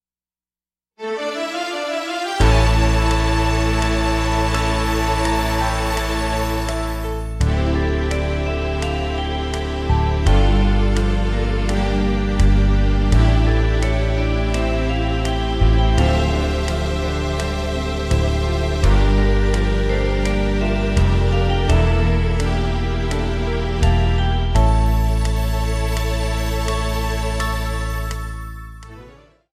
そこで上のmp３をダウンロードして頂くと、カウント付きでカラオケバージョン
そこから３拍後ピアノのソロのフレーズが流れてくるので、そこから4拍数えるとちょうど歌の出だしと